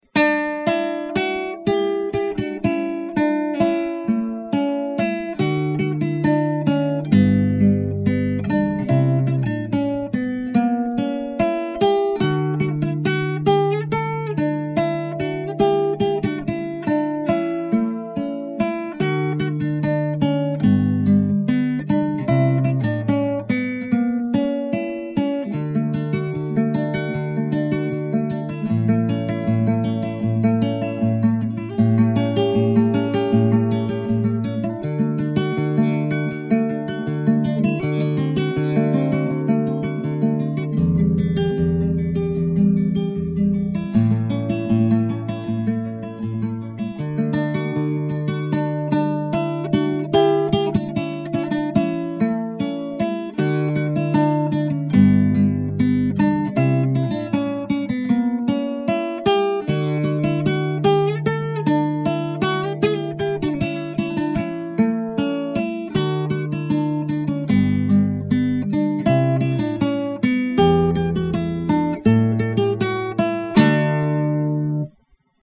ре-мажор